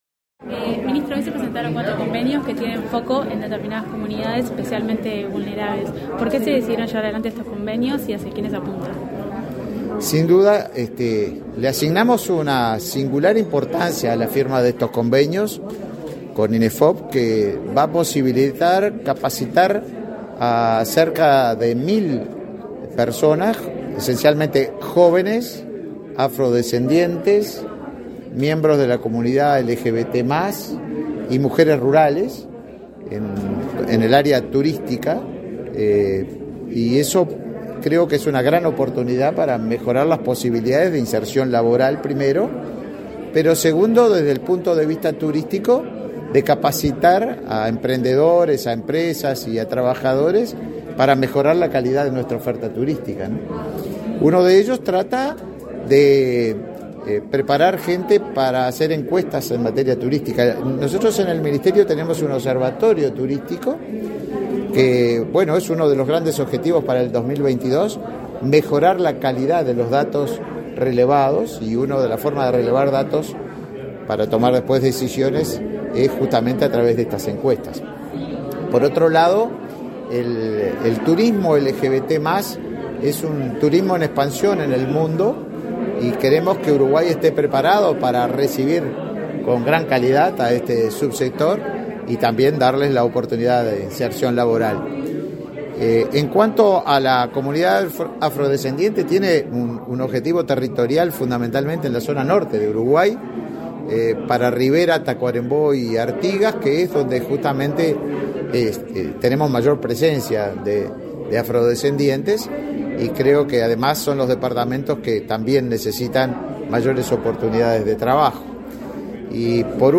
Declaraciones a la prensa del ministro de Turismo, Tabaré Viera
El Ministerio de Turismo y el de Trabajo y Seguridad Social, mediante el Instituto Nacional de Empleo y Formación Profesional (Inefop), firmaron, este 16 de marzo, cuatro convenios de capacitación orientados al sector turístico dirigidos a población LGTB+, afrodescendiente, encuestadores de turismo y mujeres emprendedoras de turismo rural. El ministro Viera explicó el alcance de los compromisos.